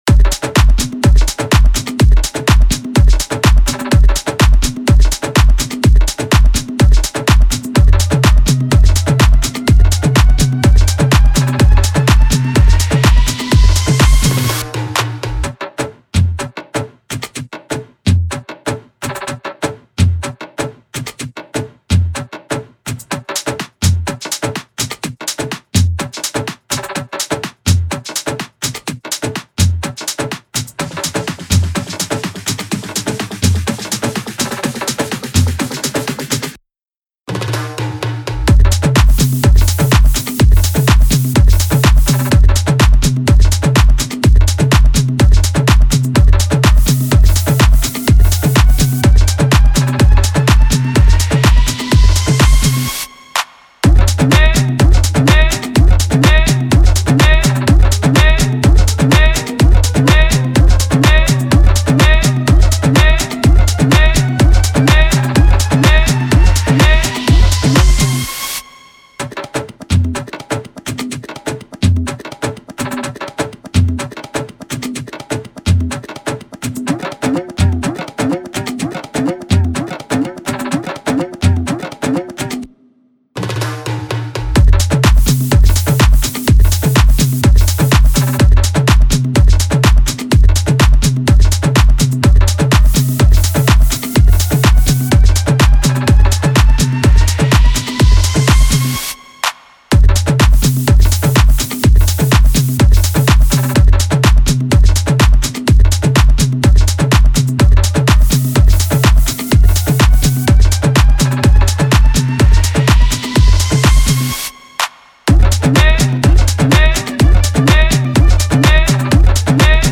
official instrumental
Dance/Club Instrumentals